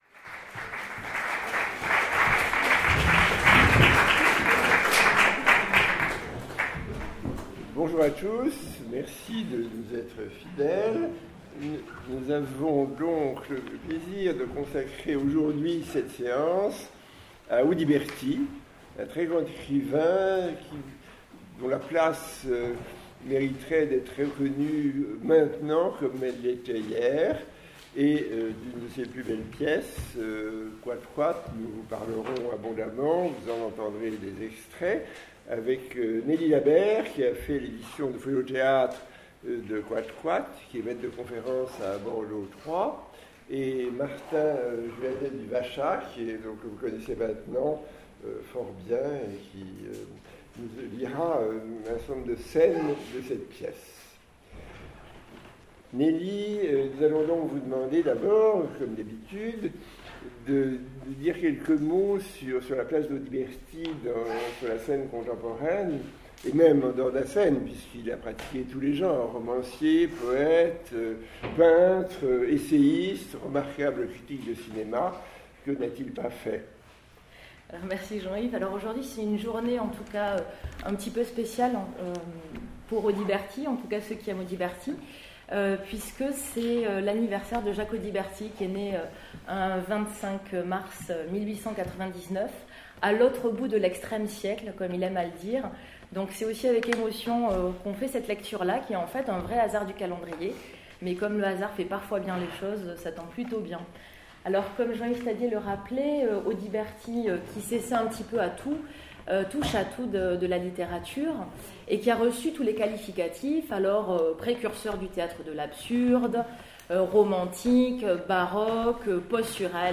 Lire le théâtre, c’est passer de la lecture silencieuse à la voix, de la voix aux voix, au jeu, au spectacle.